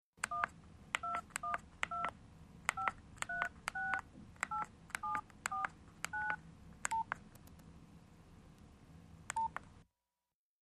Cell phone dialing with button clicks, send, end